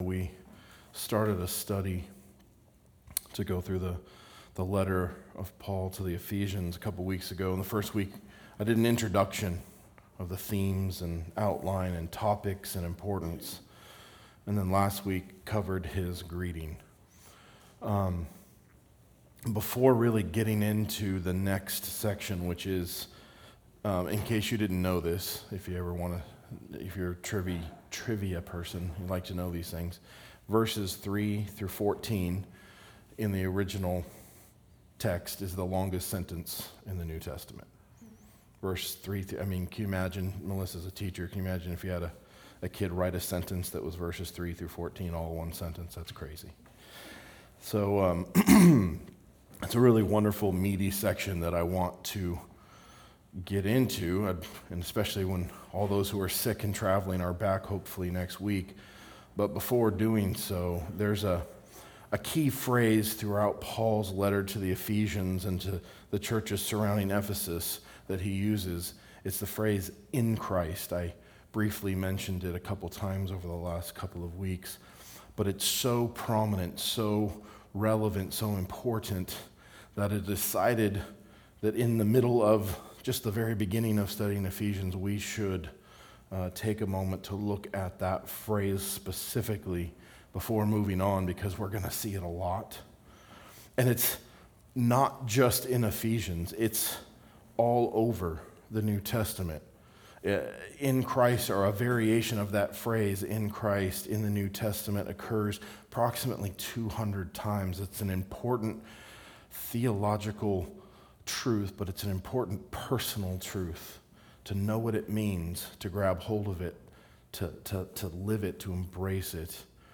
A message from the series "Ephesians."